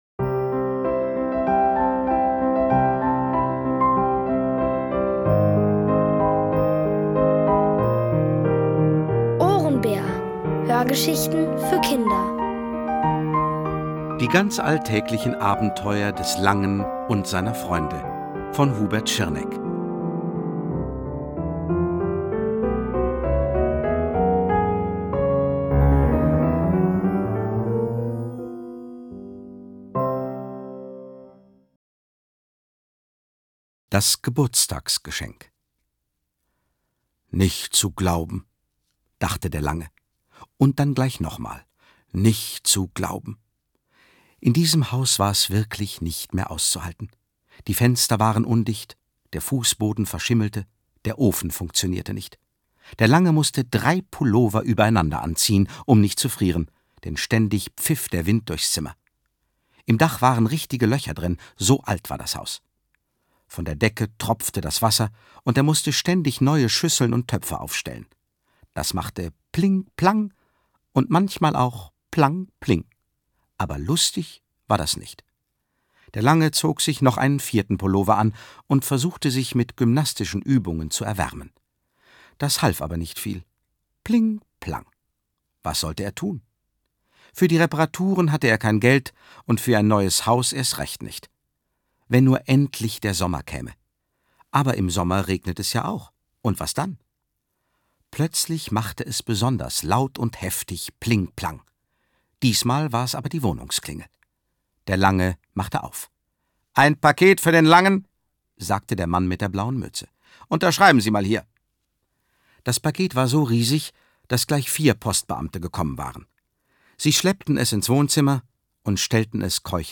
Von Autoren extra für die Reihe geschrieben und von bekannten Schauspielern gelesen.